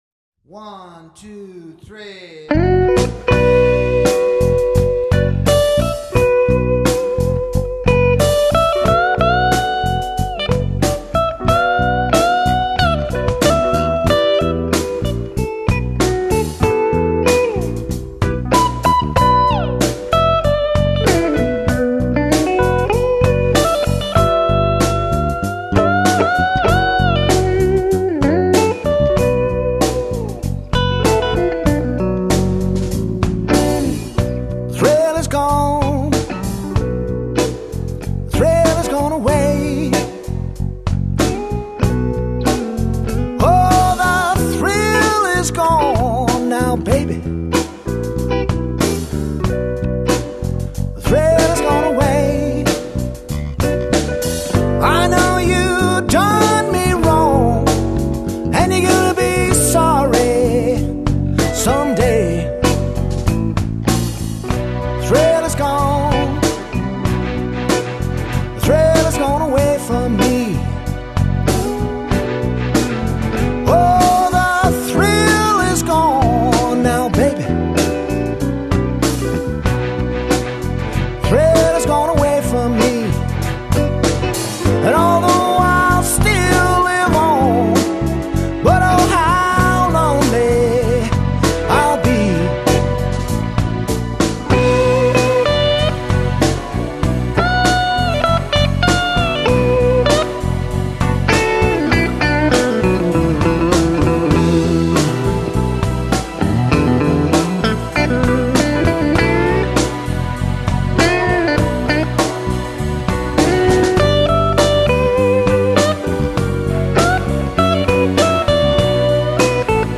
studio album, I sing & play